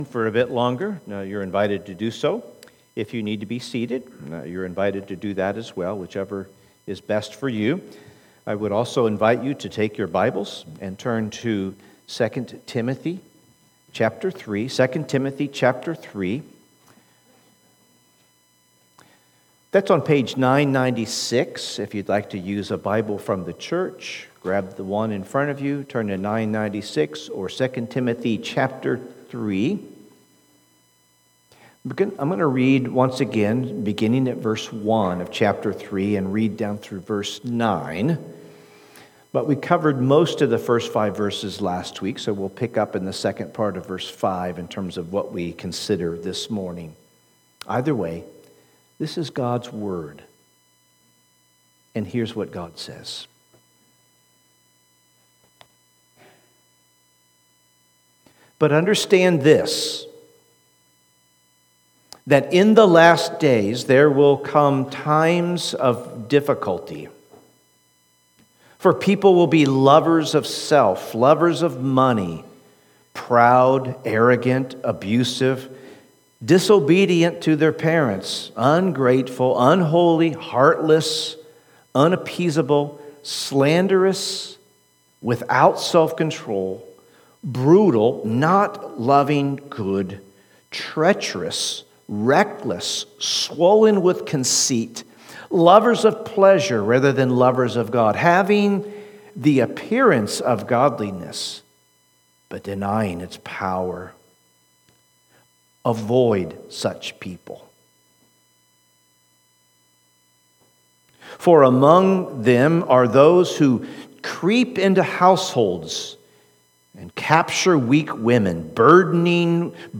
Sermons | First Baptist Church St Peters